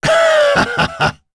Gau-Vox-Laugh.wav